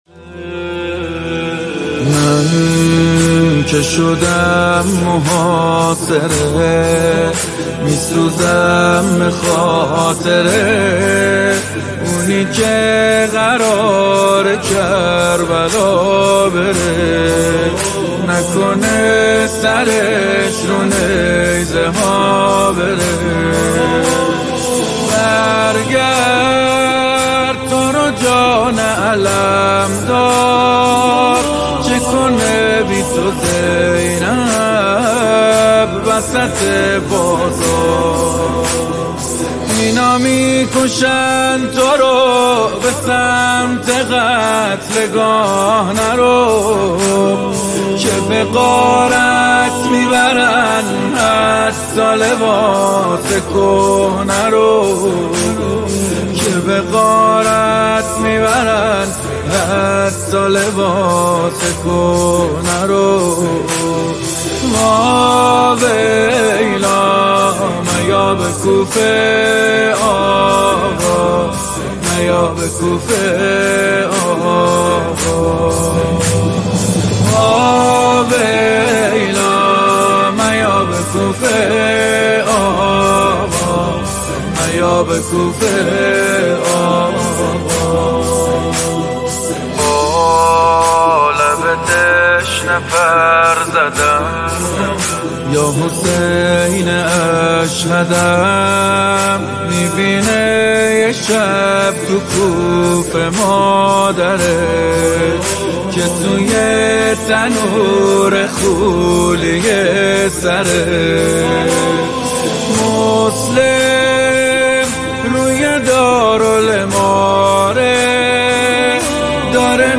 صوت مداحی